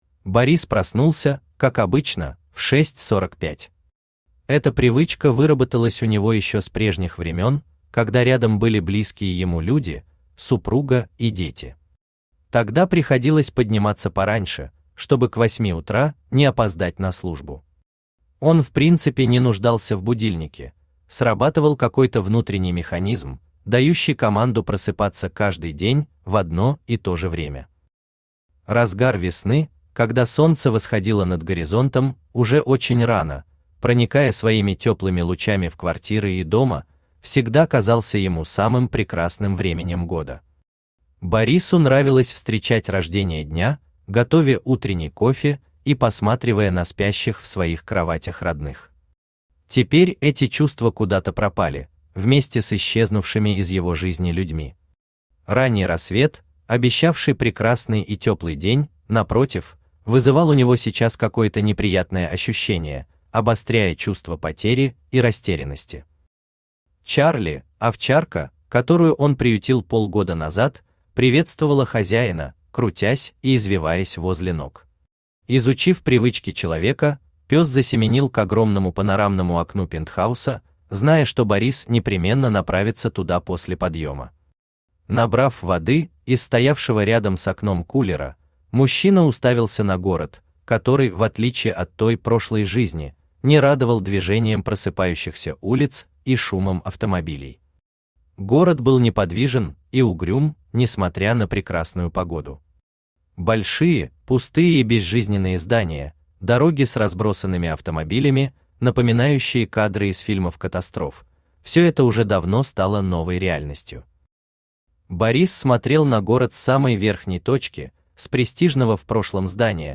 Аудиокнига Отверженные | Библиотека аудиокниг
Aудиокнига Отверженные Автор Вячеслав Александрович Егоров Читает аудиокнигу Авточтец ЛитРес.